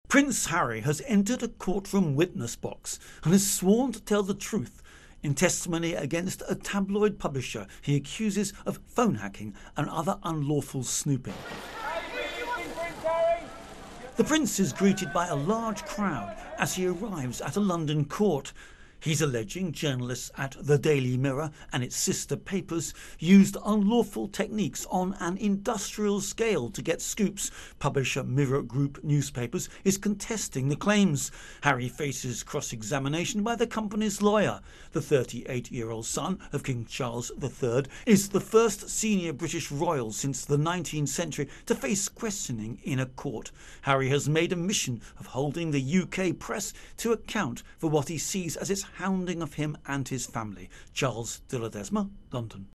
reports on Britain Prince Harry-tabloid case